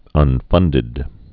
(ŭn-fŭndĭd)